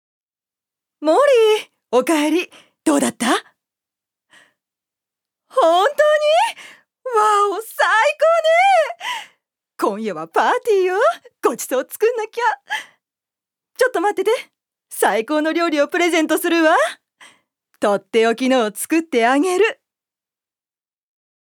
女性タレント
セリフ３